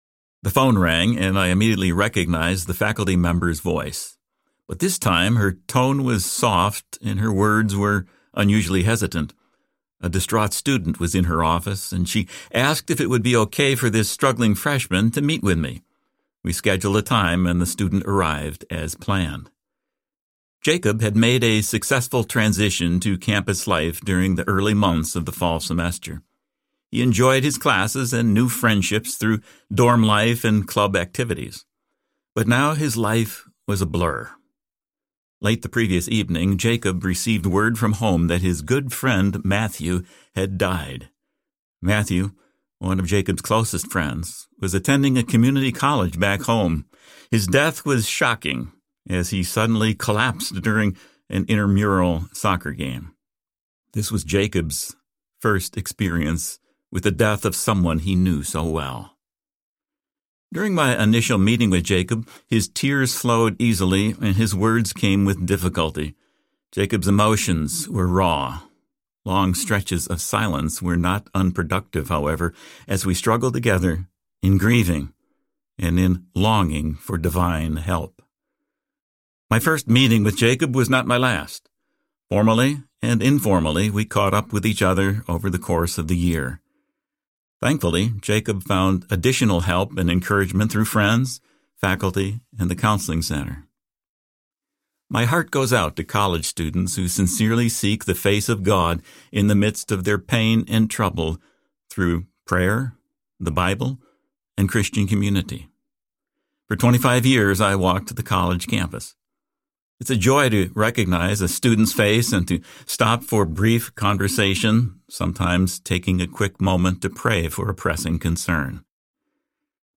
Walking with Jesus on Campus Audiobook
4.65 Hrs. – Unabridged